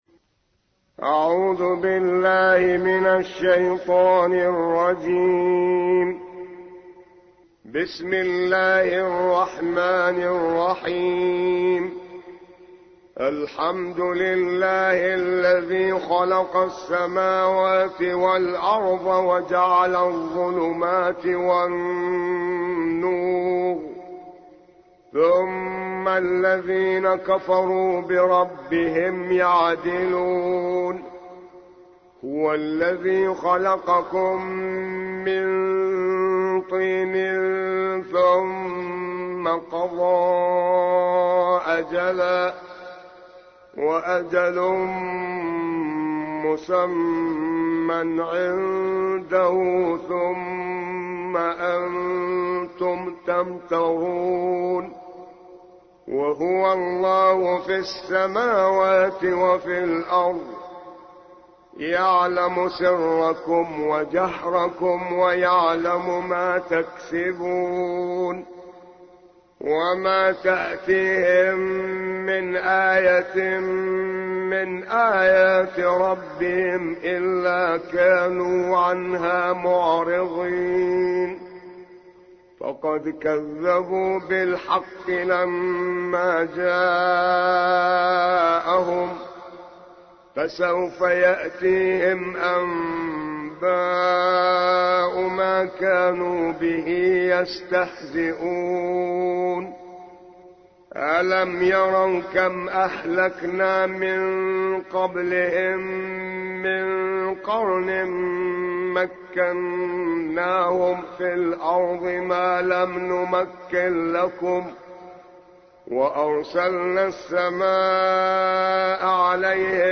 6. سورة الأنعام / القارئ